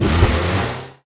car-engine-load-reverse-2.ogg